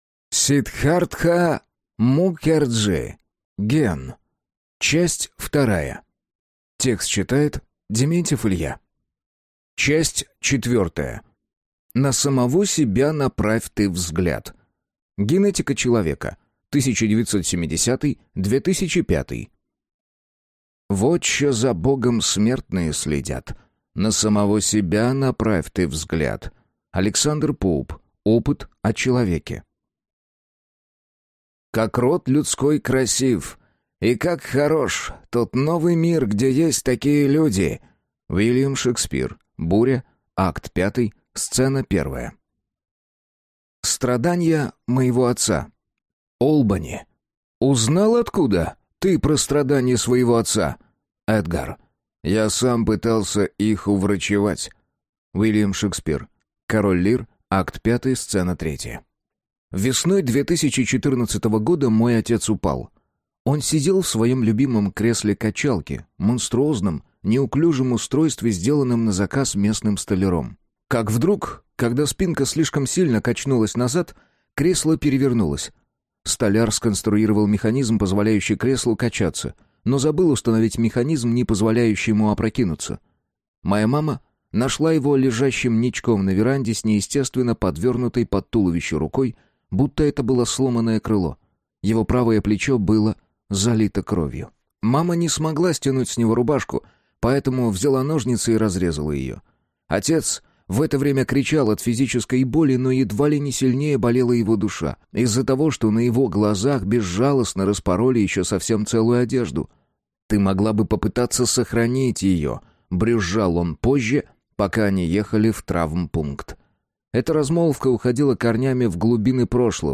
Аудиокнига Ген. Часть 2 | Библиотека аудиокниг